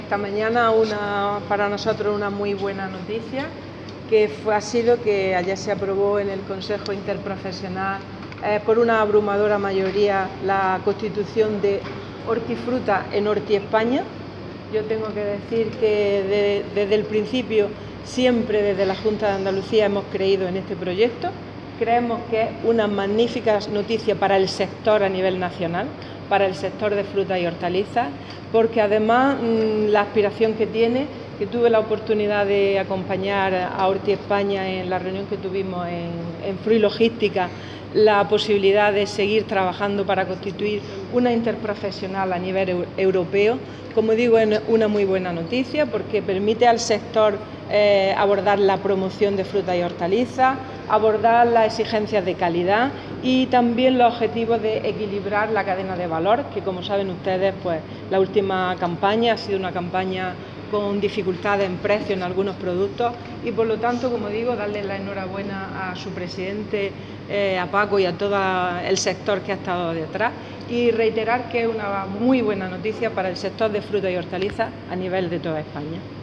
Declaraciones de Carmen Ortiz sobre el reconocimiento de Hortiespaña como interprofesional hortofrutícola a nivel estatal